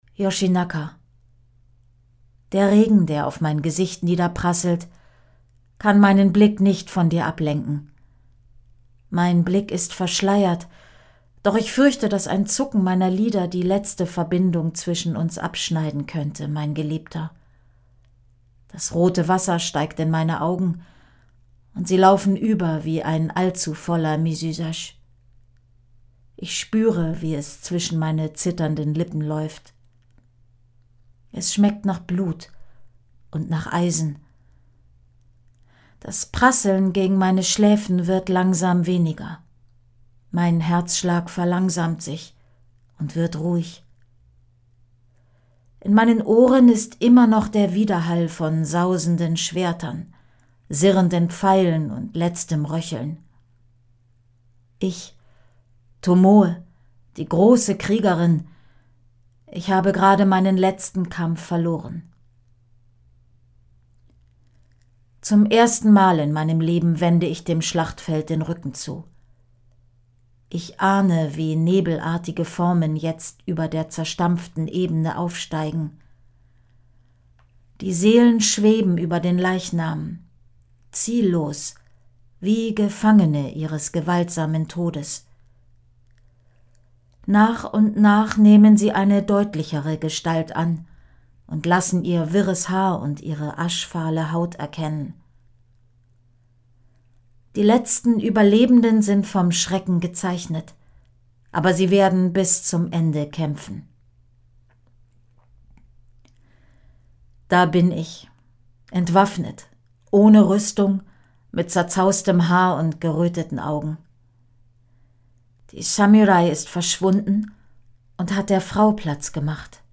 Audio/Hörbuch